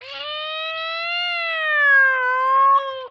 For example, to stretch the middle part of the cat's meow (which is 1.2 seconds long), you might define the following lists of time points:
The following statements construct an envelope (called a LinearEnvelope in Loris) that starts at zero, goes up to 300 cents (up three semitones, a minor third) between 0.25 and 1.25 second, down to -300 cents beteen 1.25 and 2.25 seconds, and then back to zero between 2.25 and 2.75 seconds.
This envelope, which shifts the pitch during the dilated part of the cat's meow, is the second argument to shiftPitch (the first is the partials to transform).
meow.ouch.aiff